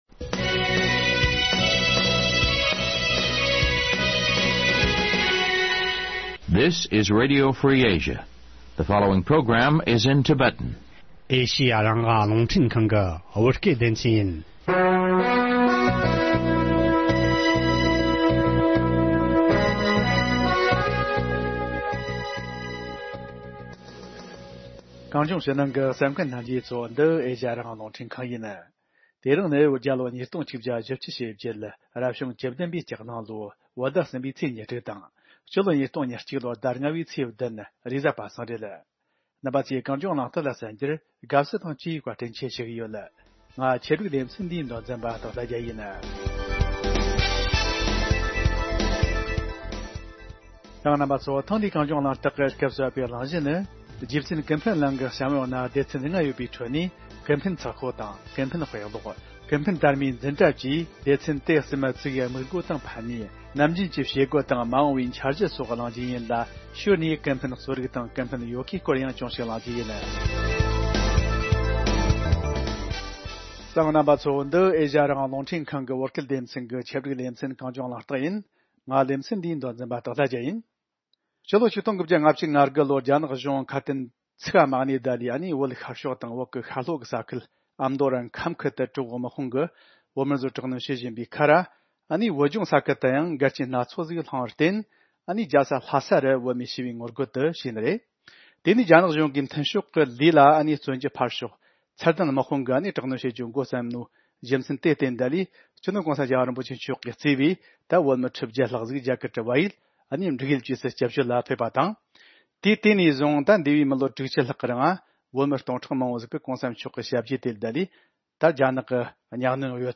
ལས་རིམ་འདིའི་སྐུ་མགྲོན་ནི་ཀུན་ཕན་རྩོམ་སྒྲིག་ཁང་གི་འགན་ཁུར་བ་བོད་མི་དམངས་སྤྱི་འཐུས་ཟུར་པ་མོག་རུ་བསྟན་པ་ལགས་ཀྱི་ལྷན་གླེང་བར་གསན་རོགས་གནོངས།།